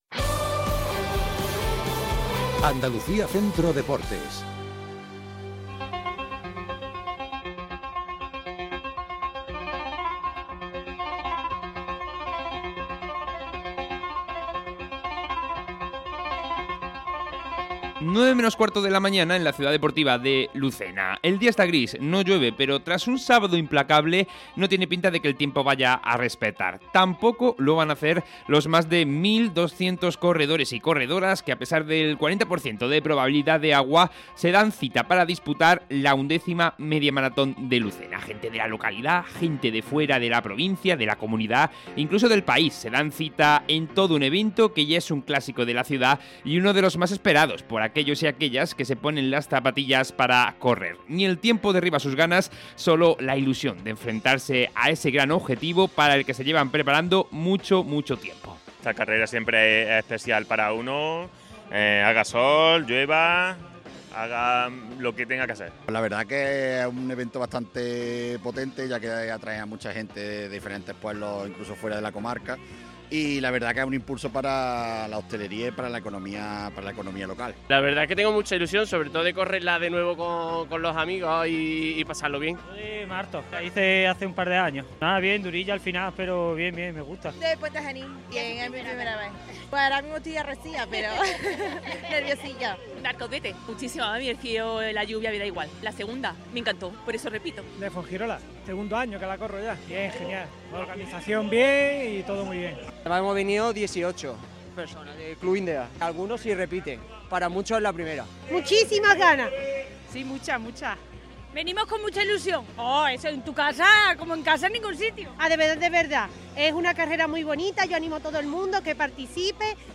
REPORTAJE | XI Media Maratón de Lucena - Andalucía Centro